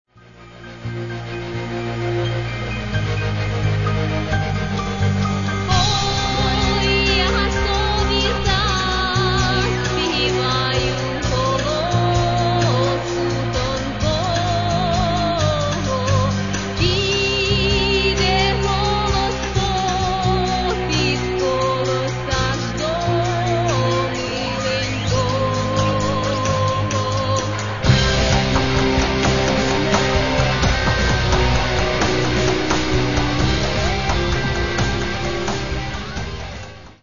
Catalogue -> Rock & Alternative -> Electronic Alternative